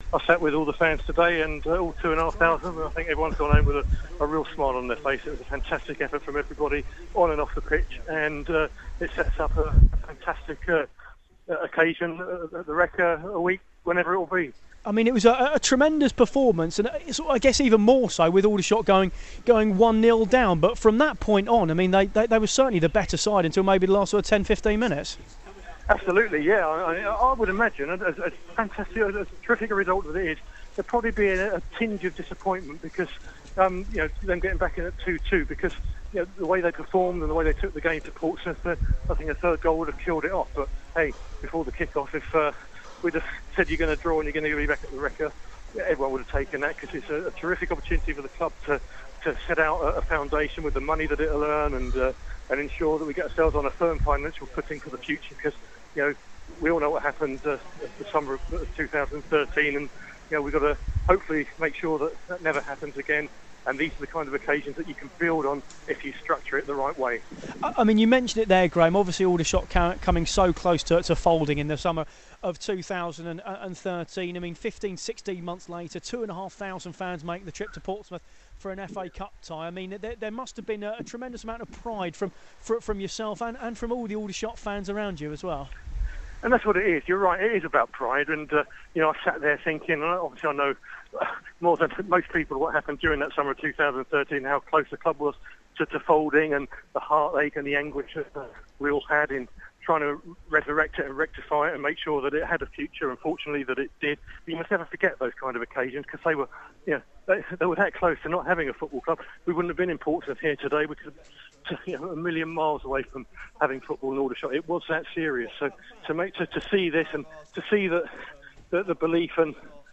Aldershot Town fan